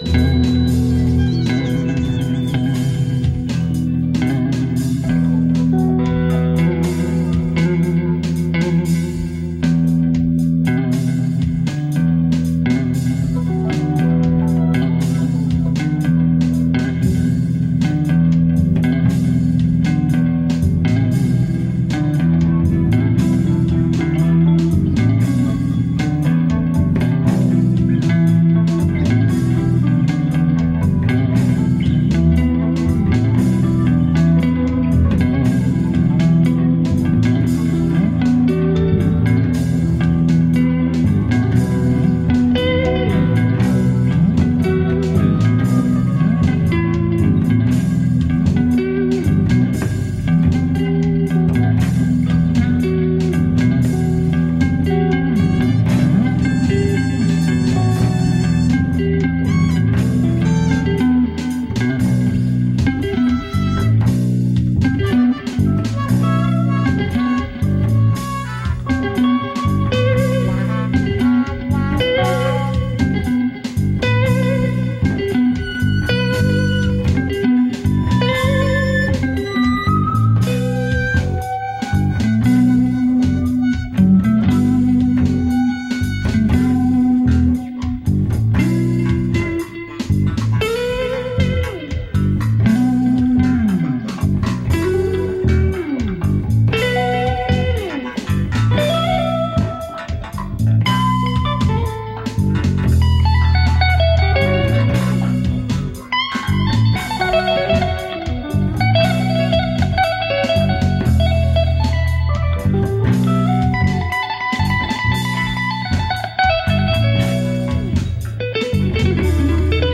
guitar
keyboards, loops
bass
guitar These MP3 files were derived from Ogg Vorbis sources, because the original WAV files have been lost to the sands of time.